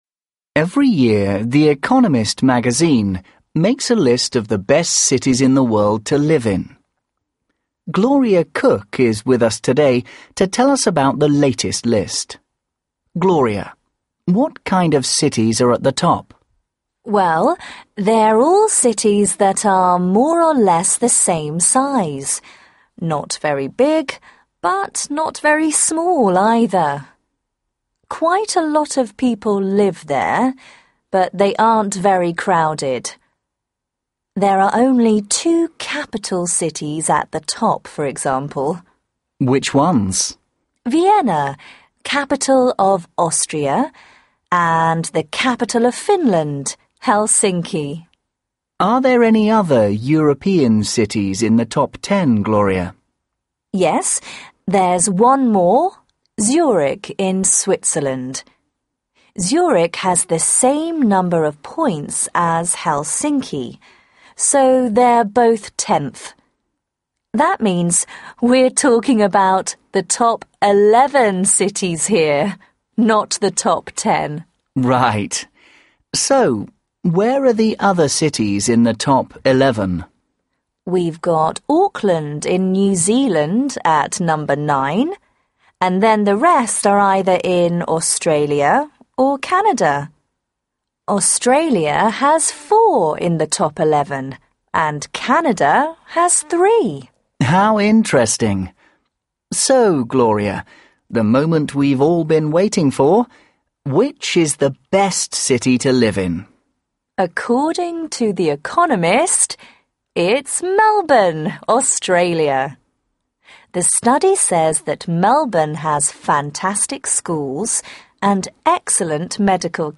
2. Listen to a radio programme about the best city to live in.